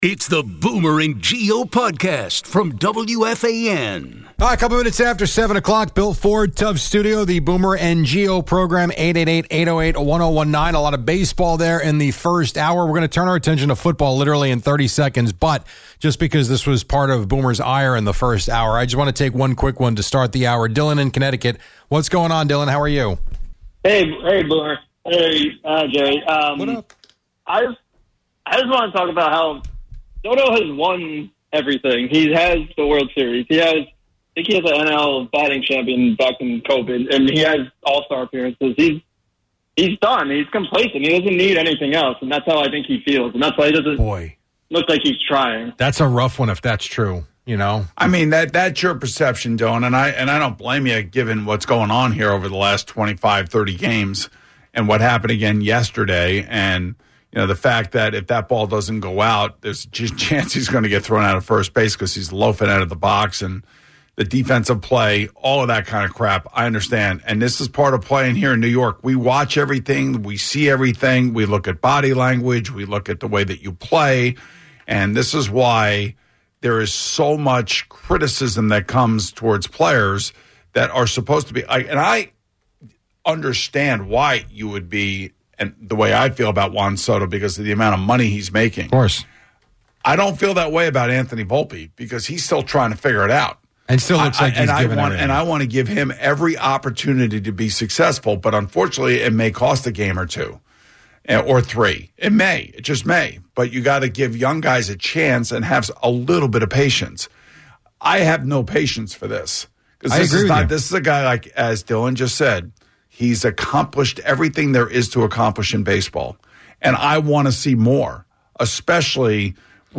The hour began with a call suggesting Juan Soto is complacent. We then discussed the Jets and Giants, noting the Giants' stability with Daboll and Schoen versus the Jets' new coach and GM, predicting growing pains.
A caller expressed confidence in the Jets making the playoffs.